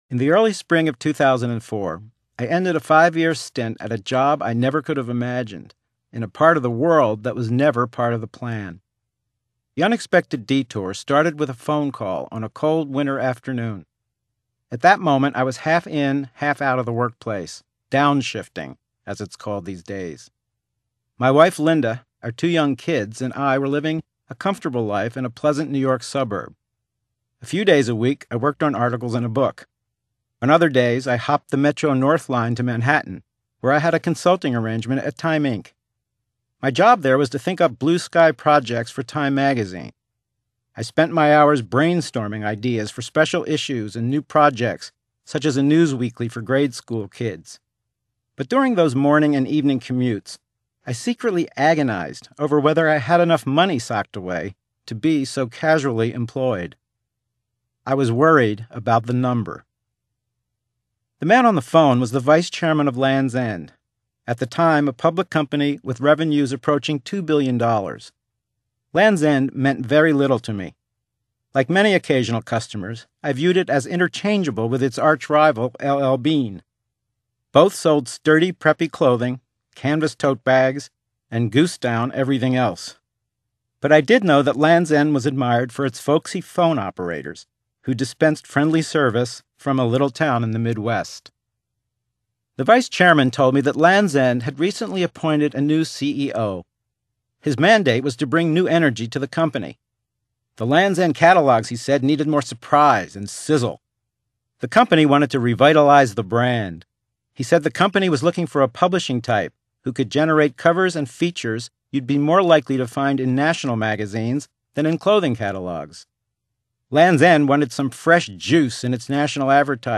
Excerpts from The Audio Book